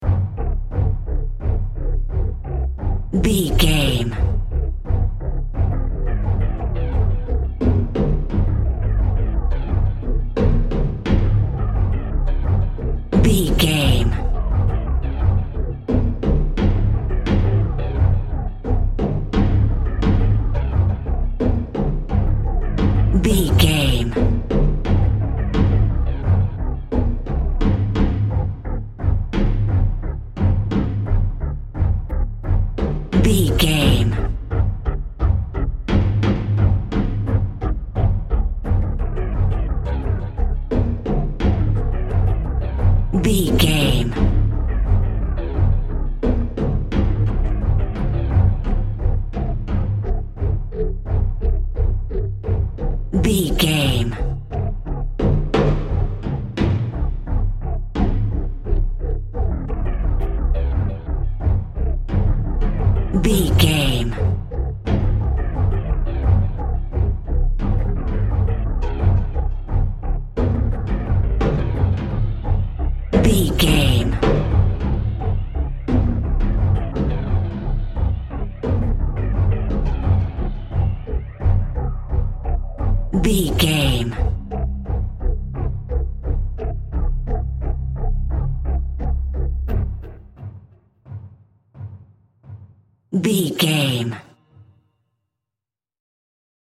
Aeolian/Minor
ominous
dark
eerie
futuristic
heavy
synthesiser
percussion
Horror synth
Horror Ambience